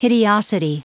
Prononciation du mot hideosity en anglais (fichier audio)
Prononciation du mot : hideosity